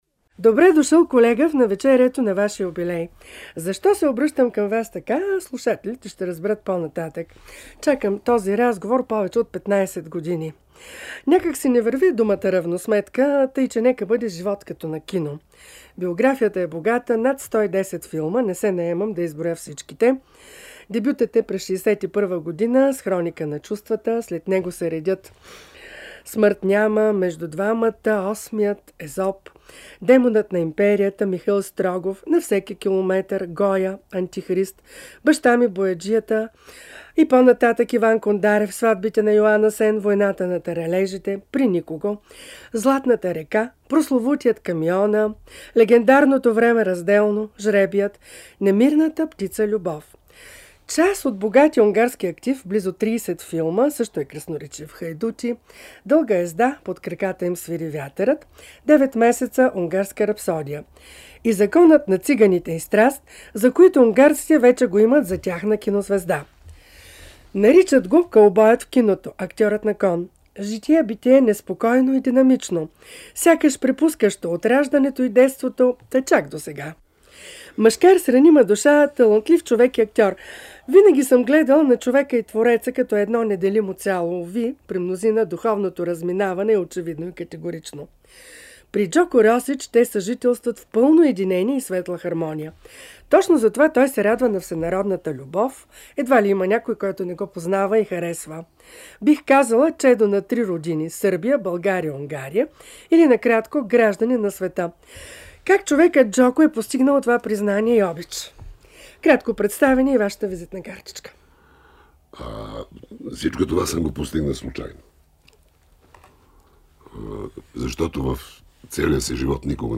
Житейският роман на Джоко Росич в интервю